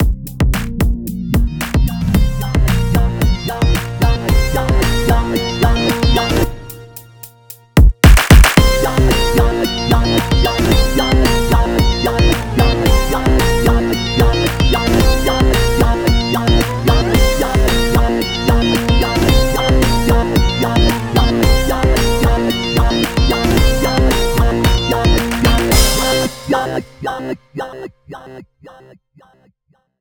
HEDGEHOG REDUX(instrumentals).wav